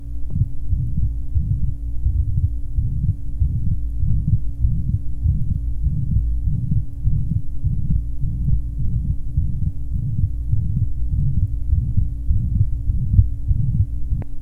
Date 1971 Type Systolic and Diastolic Abnormality Atrial Septal Defect Ostium primum ASD with mitral insufficiency. Good RV and LV filling murmurs.